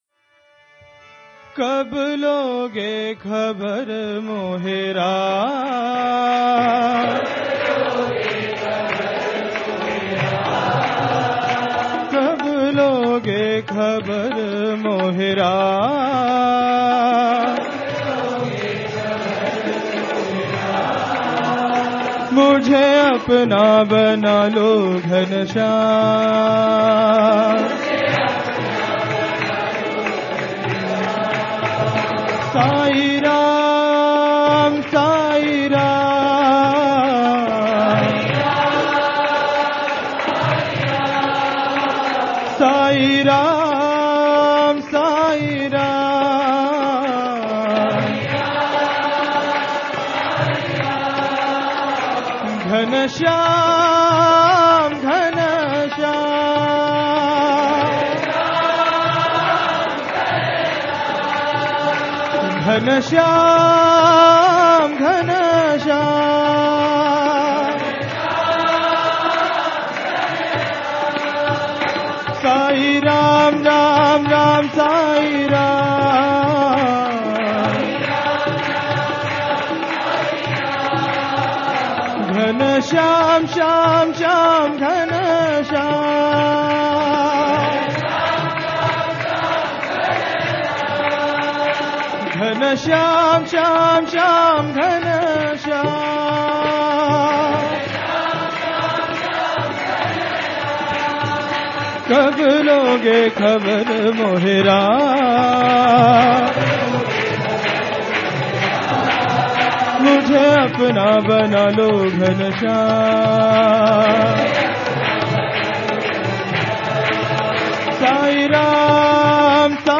1. Devotional Songs
Shankarabharanam / Bilawal 8 Beat  Men - 1 Pancham  Women - 5 Pancham
Shankarabharanam / Bilawal
8 Beat / Keherwa / Adi
1 Pancham / C
5 Pancham / G